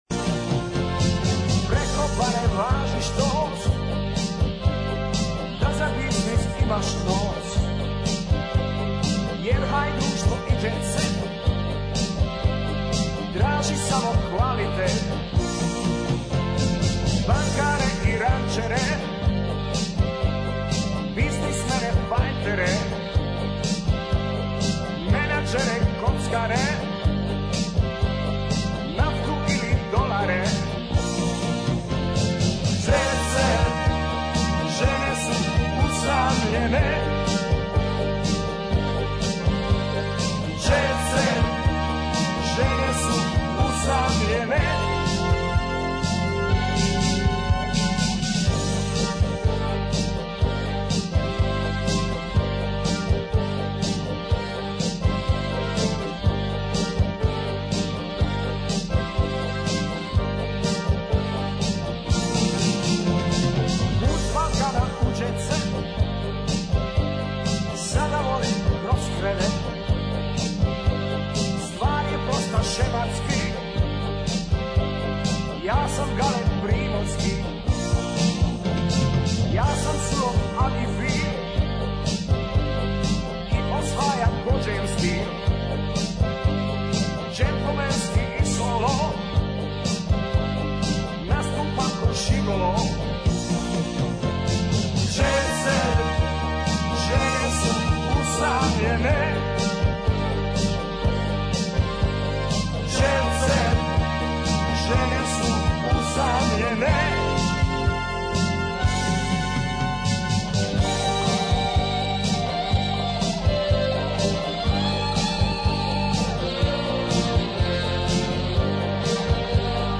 Dobra muzika non-stop!